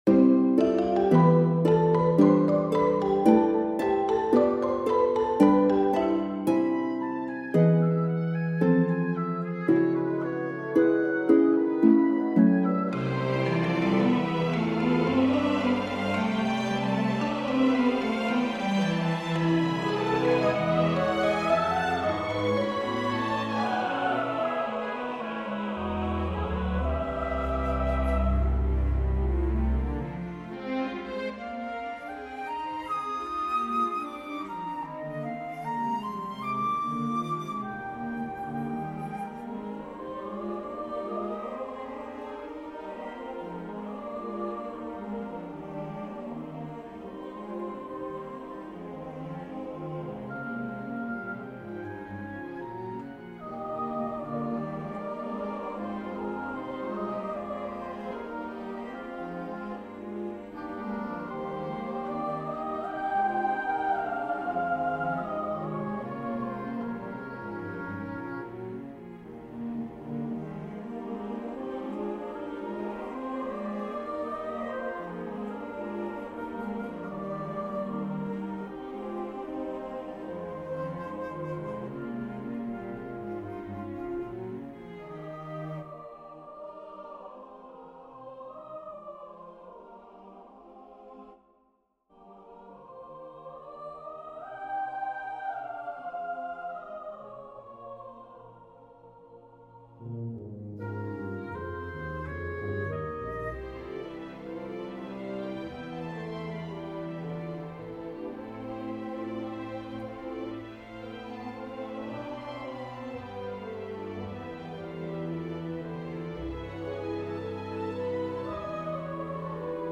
SATB chorus, piano